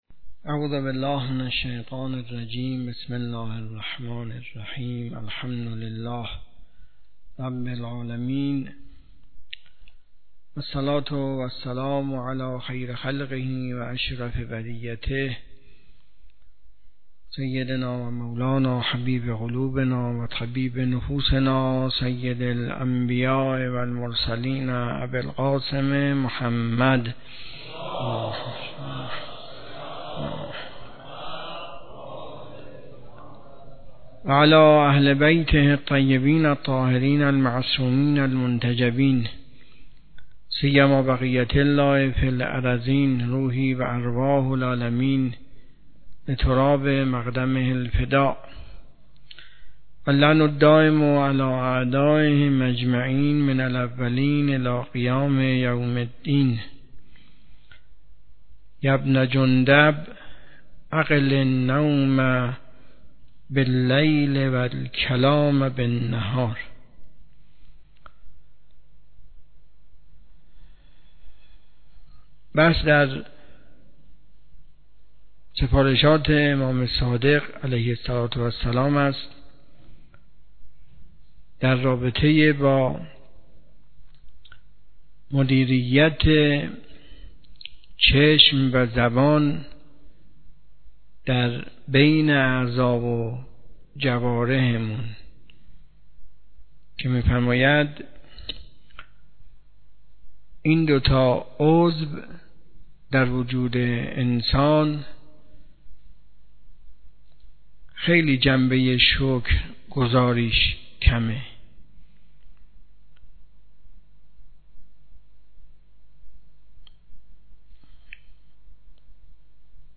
حوزه علمیه معیر تهران